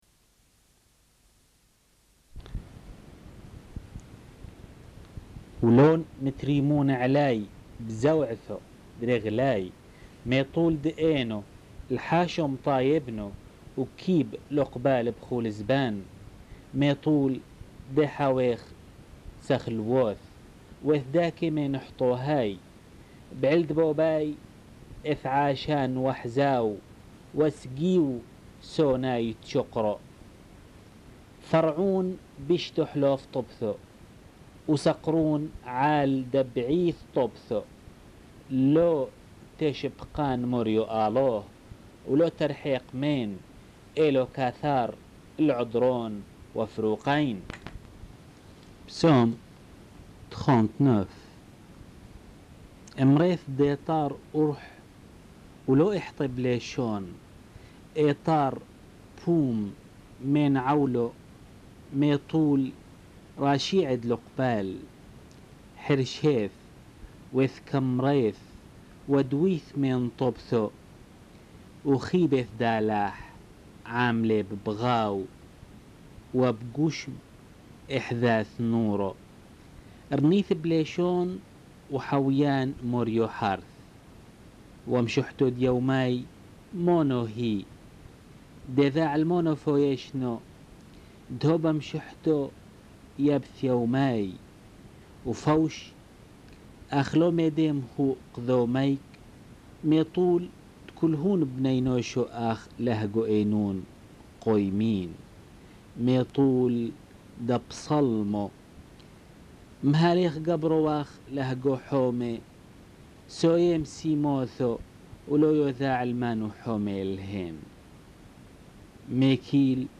Enregistrement de la lecture des Psaumes (version syriaque)